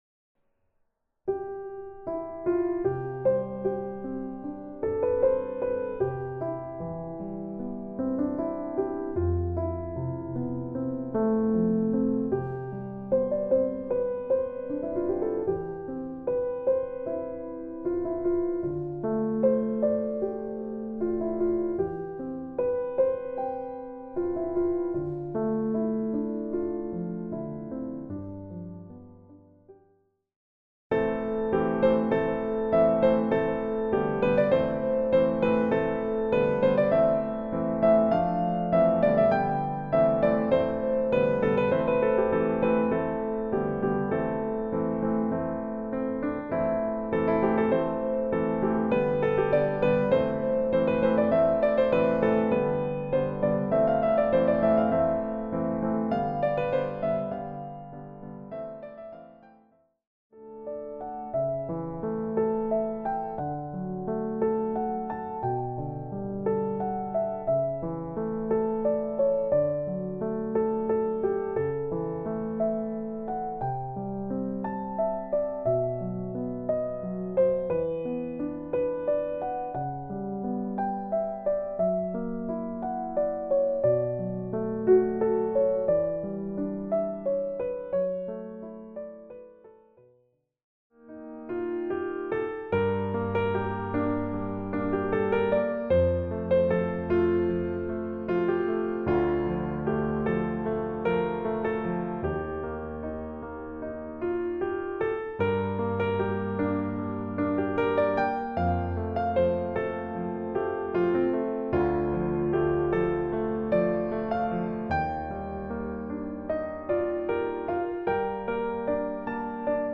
• -Recueil pour piano solo
• -Illustrations musicales pleines de douceur et de poésie
Instrumentation : Piano
Genre:  Classique, romantique, New-age
• Piano solo collection
• Musical illustrations full of gentleness and poetry